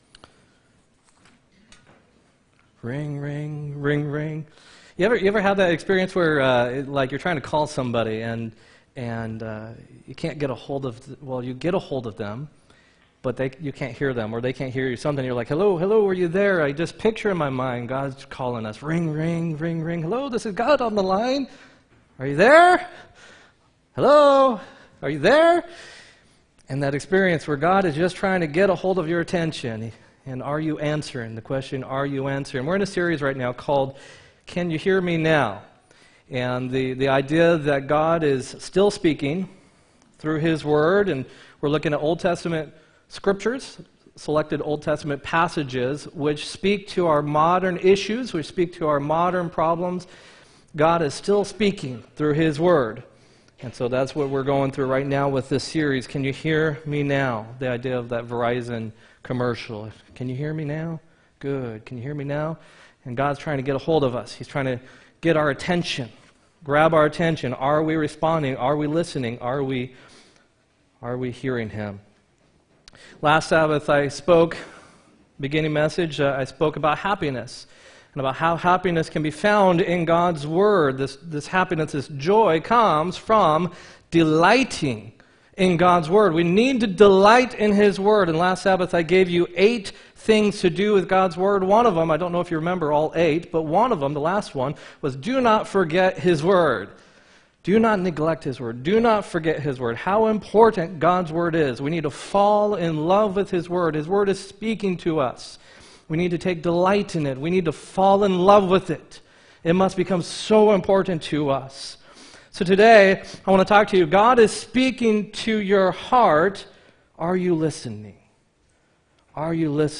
2-10-18 sermon